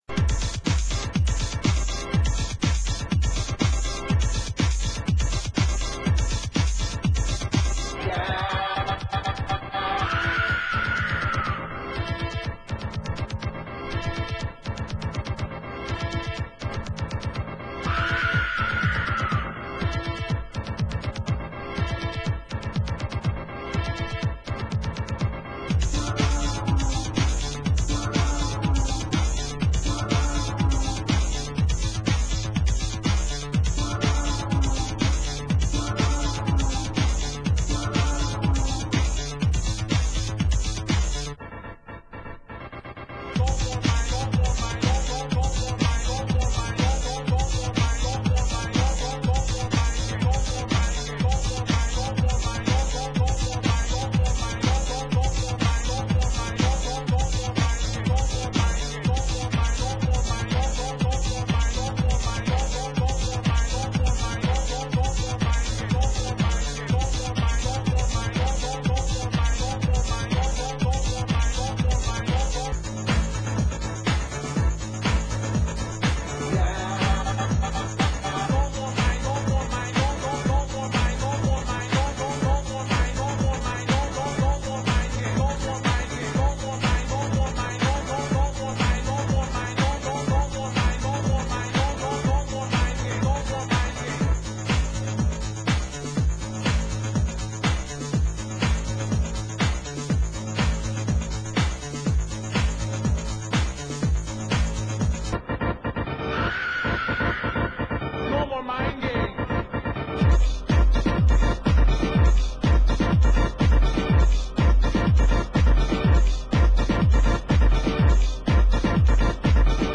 Genre: Euro Techno